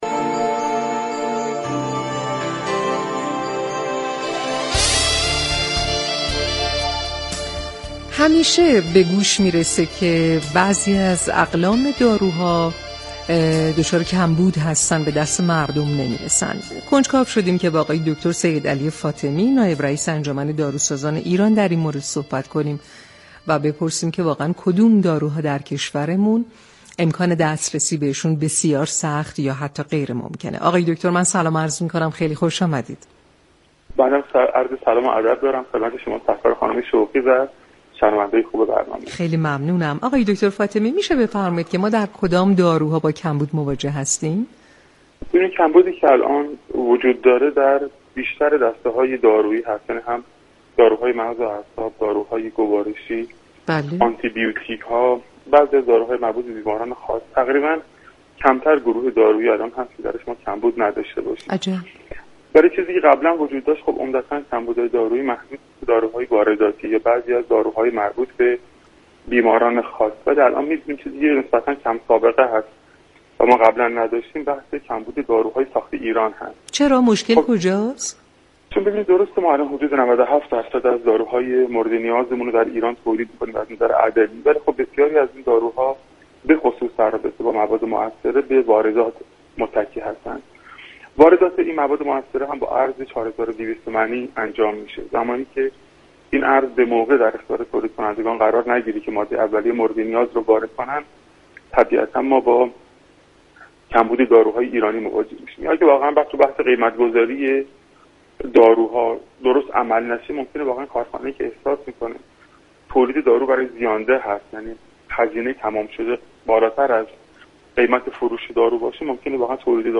در گفتگو با برنامه تهران ما سلامت رادیو تهران